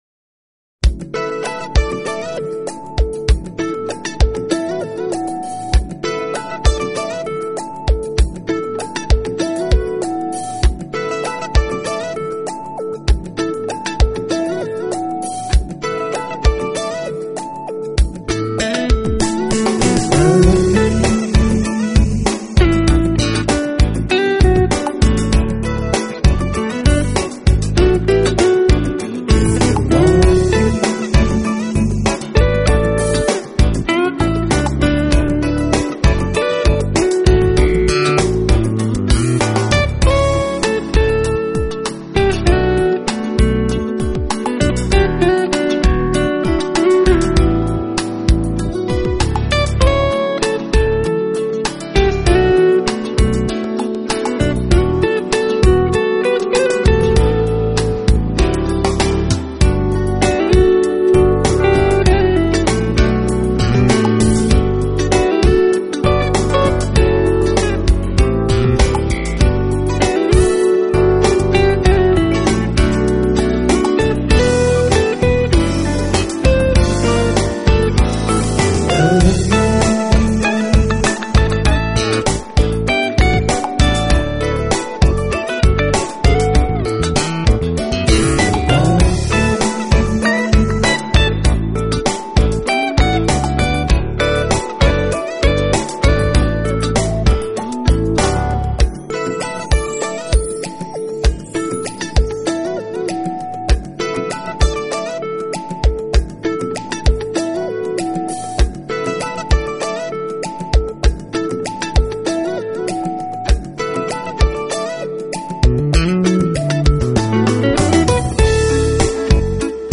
节奏欢快，旋律优美。
Exactly the nucleus of fine smooth jazz guitar music.